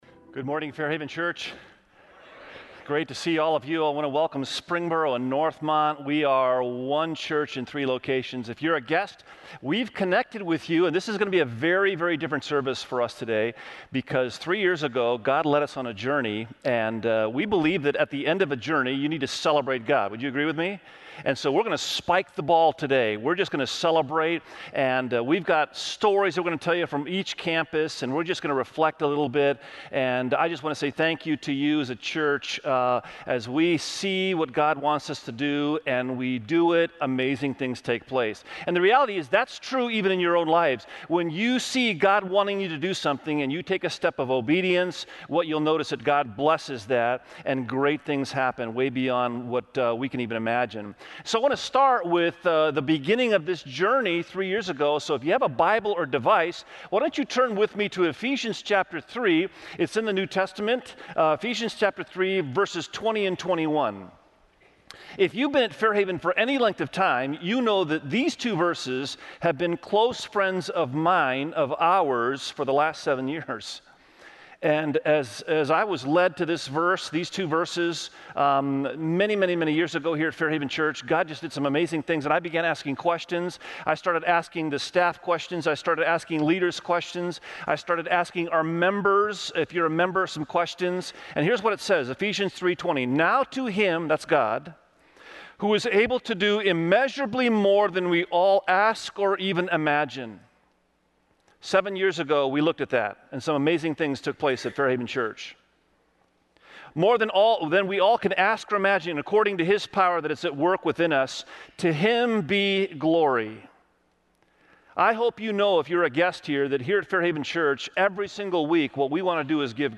Check out Reach, a sermon series at Fairhaven Church.
Sermon Series